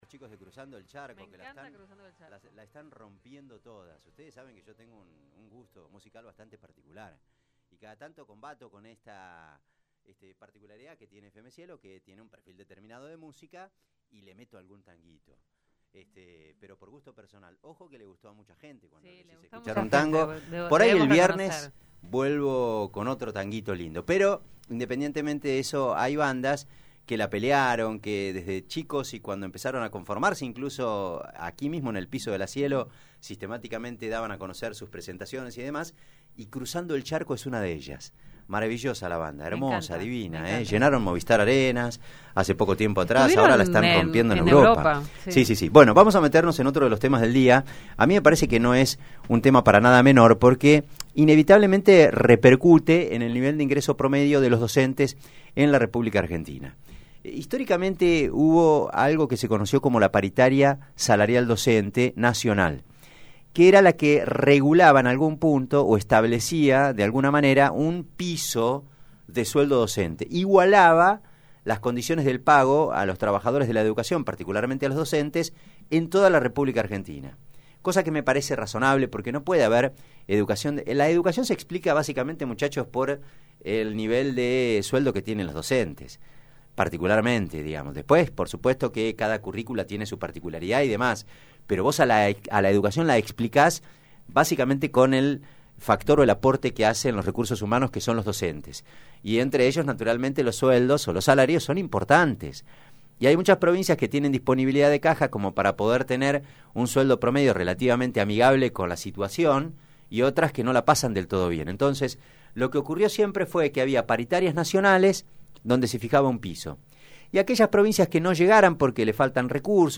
La situación educativa en la Provincia de Buenos Aires se ha vuelto cada vez más compleja desde que la Federación de Educadores Bonaerenses (FEB) convocó su primer paro al gobierno provincial de Axel Kicillof. En este contexto, conversamos con Alberto Sileoni, Director General de Cultura y Educación de la Provincia.